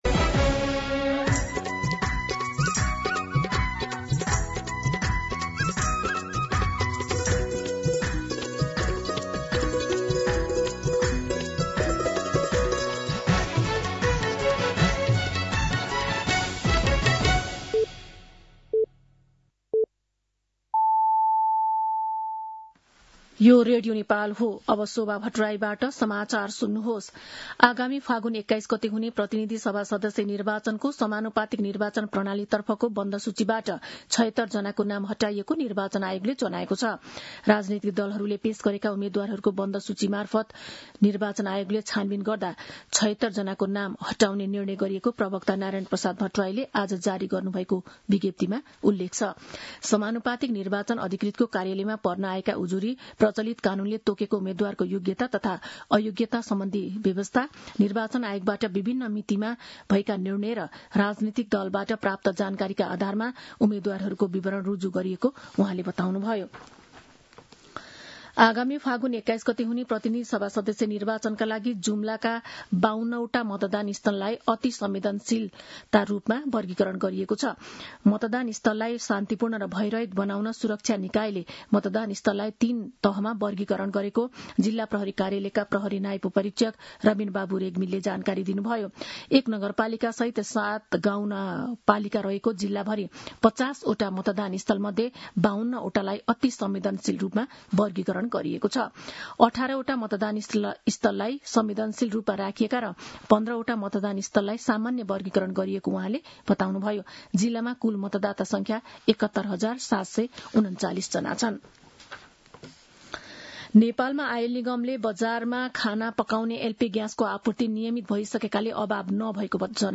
दिउँसो १ बजेको नेपाली समाचार : २० माघ , २०८२